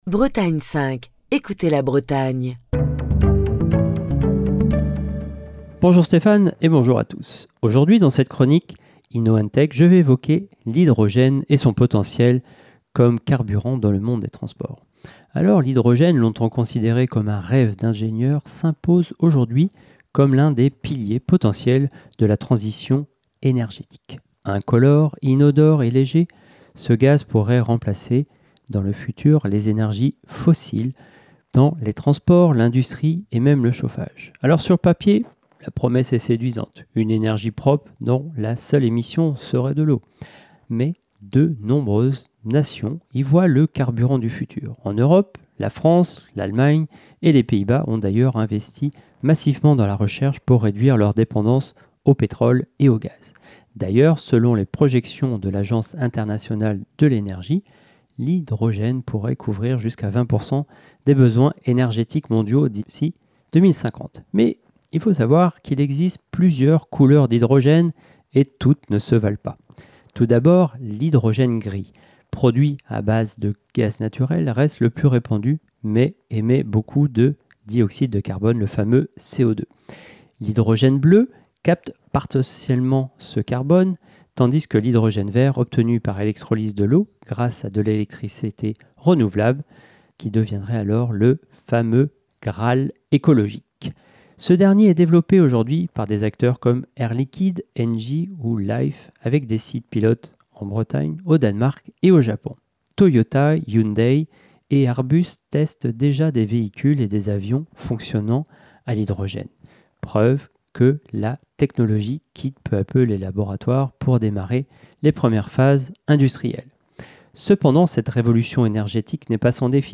Chronique du 22 octobre 2025.